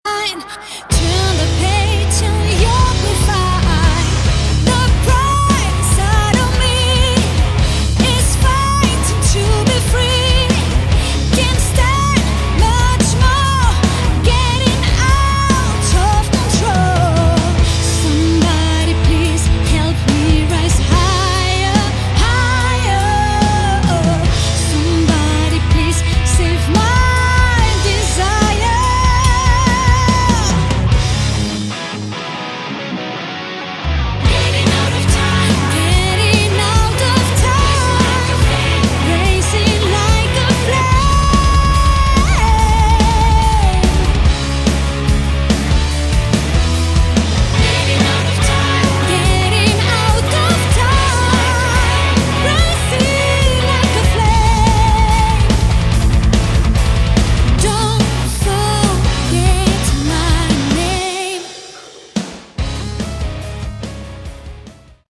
Category: Melodic Rock
vocals
guitar
drums
bass